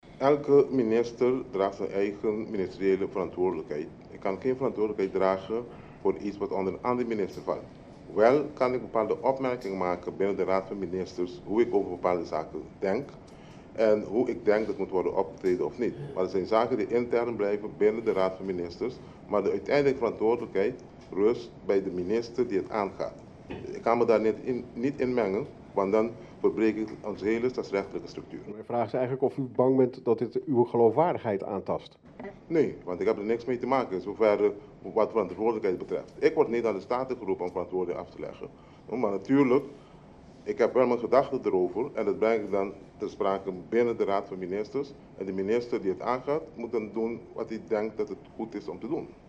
WILLEMSTAD – De politietop en minister van Justitie Nelson Navarro kwamen donderdag speciaal opdraven op de wekelijkse persconferentie van de ministerraad In Fort Amsterdam.
Ik vroeg het aan Navarro tijdens de persconferentie, dit is wat hij mij als antwoord gaf: